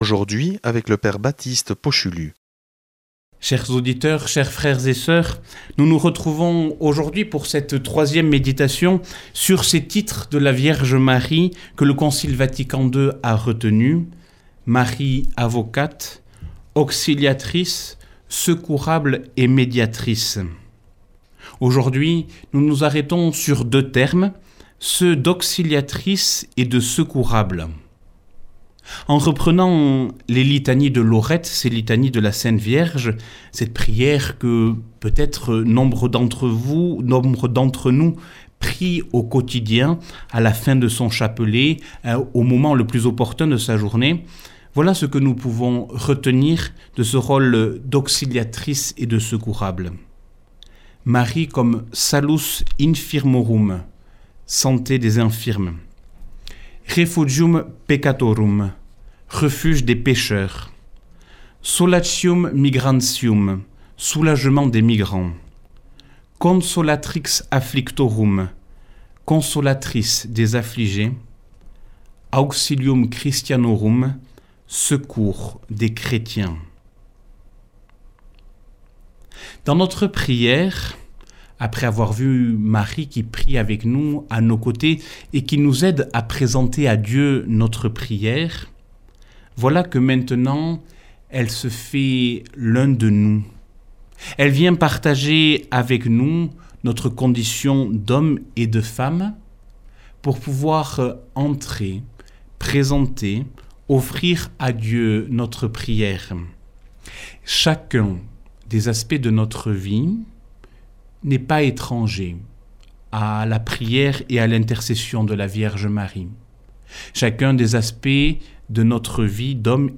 Enseignement Marial du 06 mars